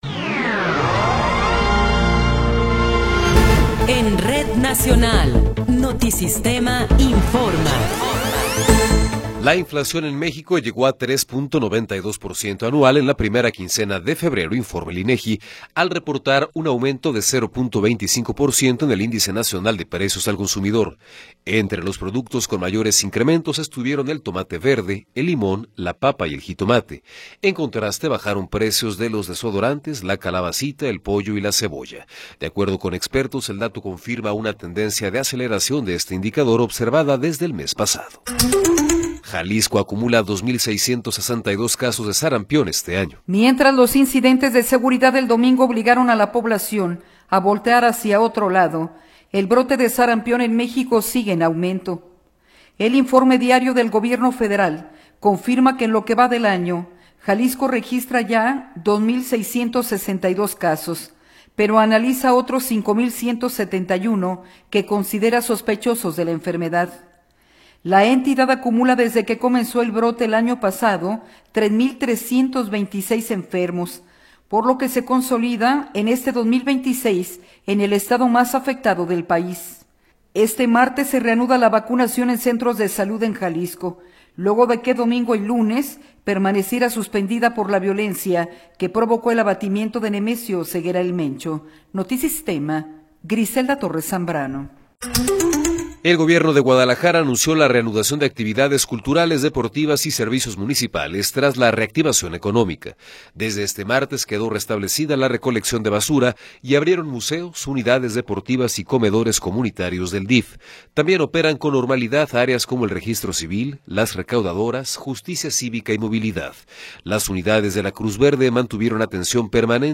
Noticiero 12 hrs. – 24 de Febrero de 2026
Resumen informativo Notisistema, la mejor y más completa información cada hora en la hora.